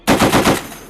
.50 cal bursts
Isolated from a test firing range video.
Burst lengths vary a little, it will give your unit a little variety so as not to sound repetitive.